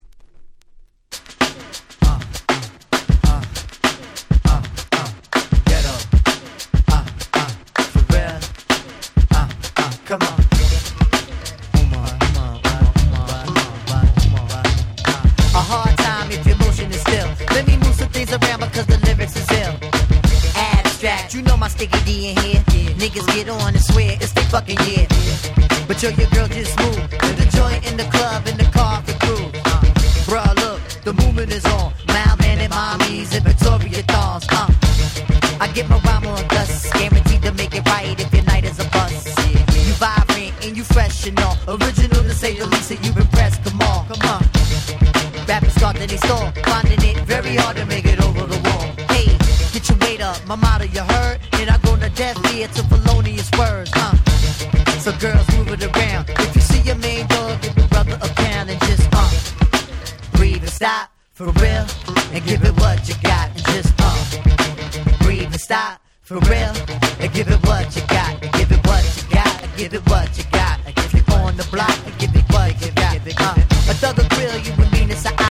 Late 90's Hip Hop Classics !!